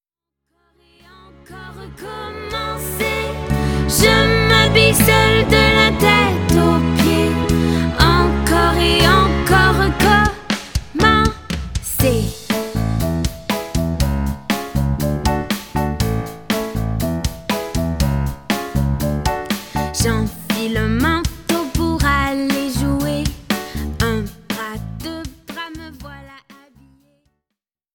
en version chantée et en version instrumentale